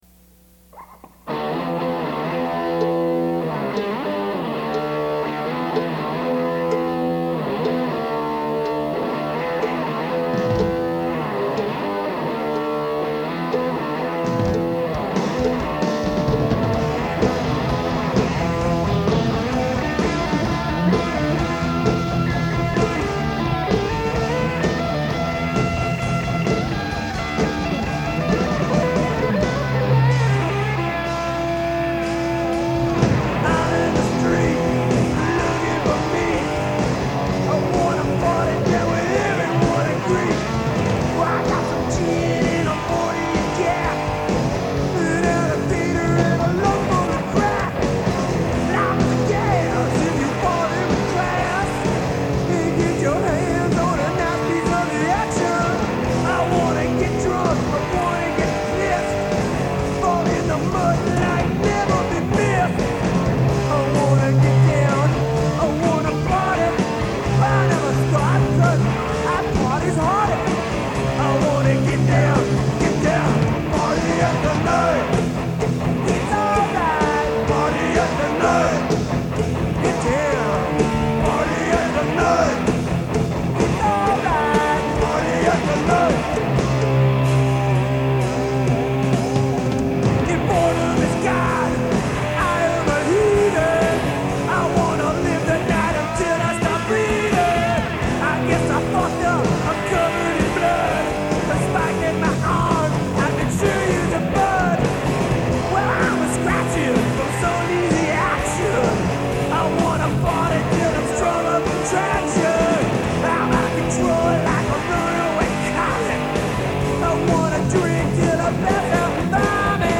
The songs were originally recorded using an 8 track.
That's right folks, two, count 'em, two drummers!